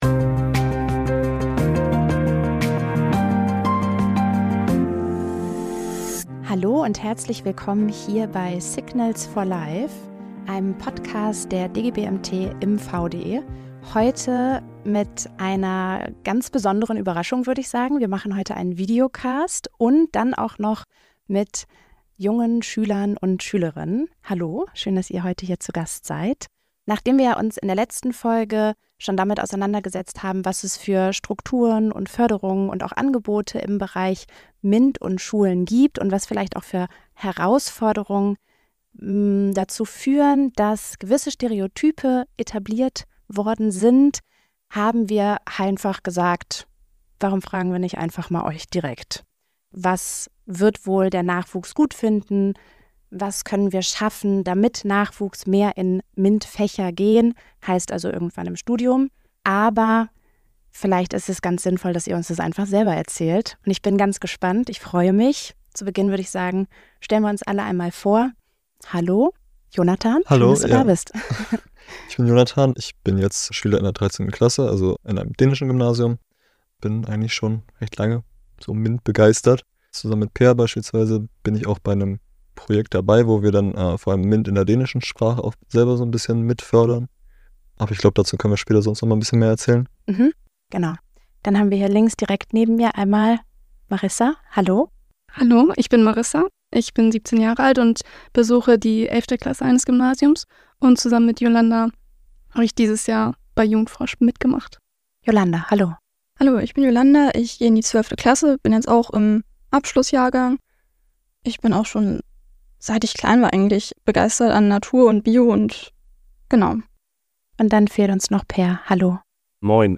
#60 MINT-Jugend forscht – Vier junge Stimmen über ihre Erfahrungen und Ideen